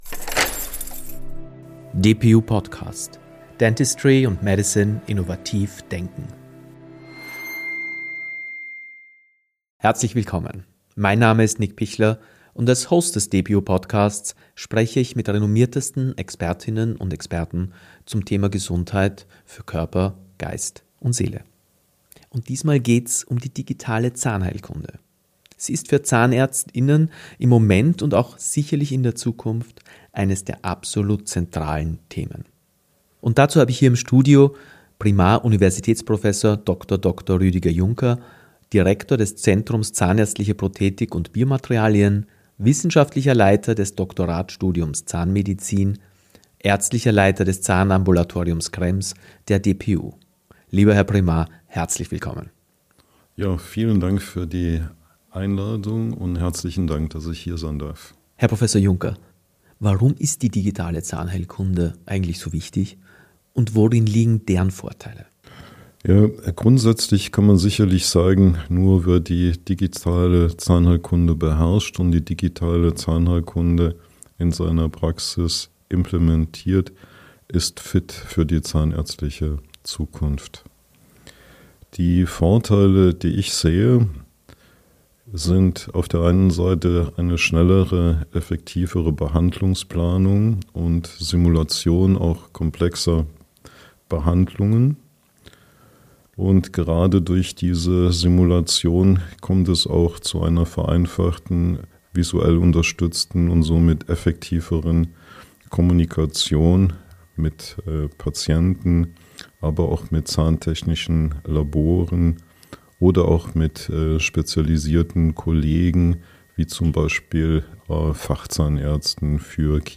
Ein Gespräch über Präzision, Effizienz und die zentrale Bedeutung digitaler Entwicklungen für die Zahnmedizin von morgen.